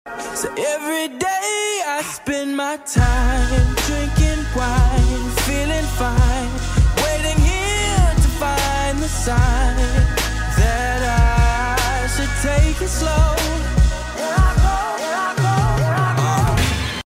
Sunset, Sea, Friends & Boat Sound Effects Free Download